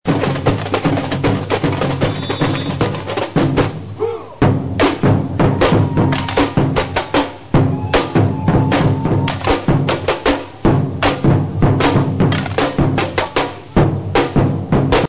batucada Bresil